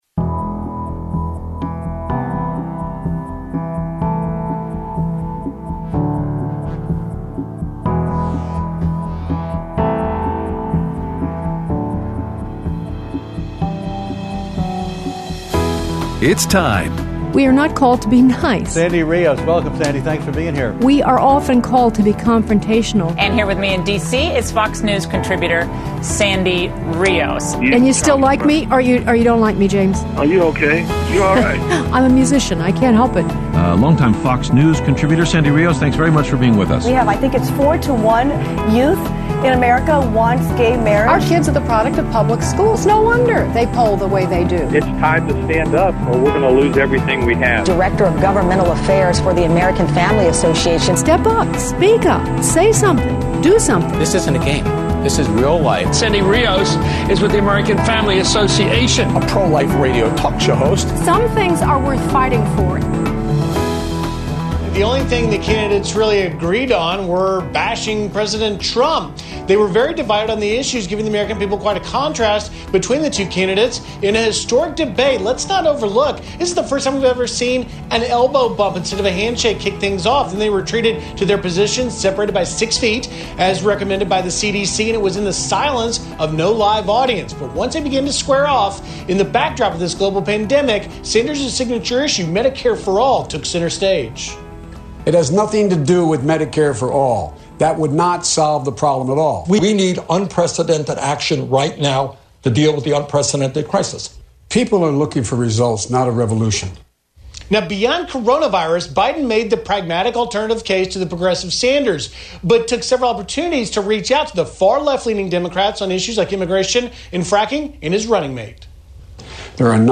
Aired Monday 3/16/20 on AFR 7:05AM - 8:00AM CST